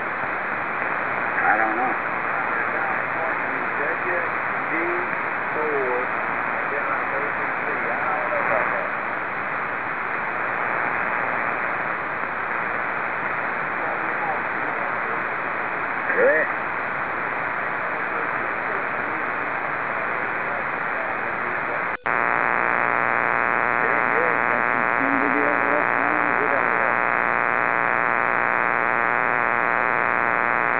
The noise had a characteristic that led me to believe it was derived from a 60 Hz power line.
If I turned off the MFJ-1026 the noise immediately was S9+5 dB.
I recorded four wave file snippets (.wav) using my FT1000MP that show the effect of the MFJ-1026.
In the following examples I turn the MFJ on and off so you can really hear the effect.
75m SSB with MFJ-1026 first ON, then OFF
MFJ-1026 on-off.wav